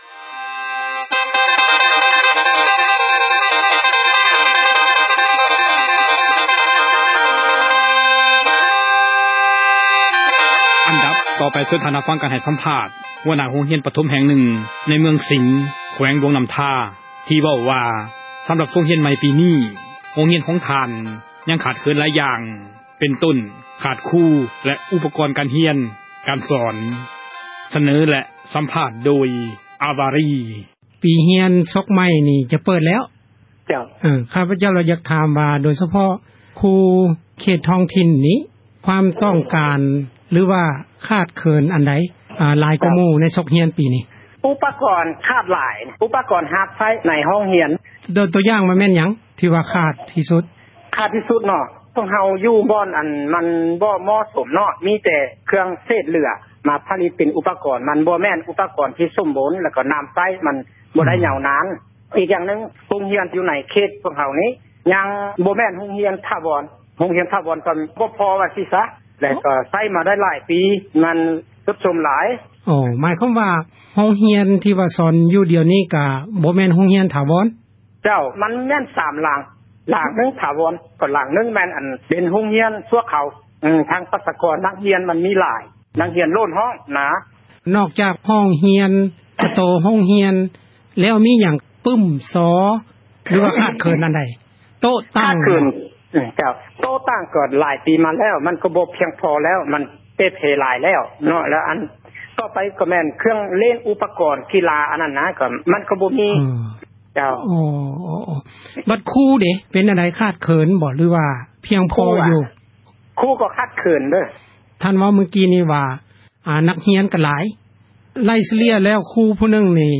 ການສຳພາດ ຫົວໜ້າ ໂຮງຮຽນ ປະຖົມ ໃນ ເມືອງສິງ ແຂວງ ຫລວງນ້ຳທາ ກ່ຽວກັບ ສົກຮຽນ ໃໝ່ ປີນີ້ ຊຶ່ງໃນ ໂຮງຮຽນ ຂອງ ທ່ານ ຍັງຂາດເຂີນ ຄຣູ ແລະ ອຸປກອນ ການຮຽນ ການສອນ.